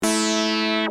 Free MP3 vintage Korg Polysix loops & sound effects 10
Korg - Polysix 182